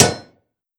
Blacksmith hitting hammer 8.wav